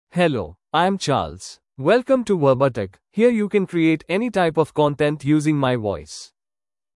Charles — Male English (India) AI Voice | TTS, Voice Cloning & Video | Verbatik AI
MaleEnglish (India)
Charles is a male AI voice for English (India).
Voice sample
Charles delivers clear pronunciation with authentic India English intonation, making your content sound professionally produced.